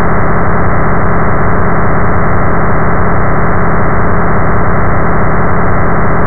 Uses Node.js parse errors and sh pipes to generate ‘noise’ from the pits of hell.
Sample Rate: 4000 Hz
Channels: 1 (mono)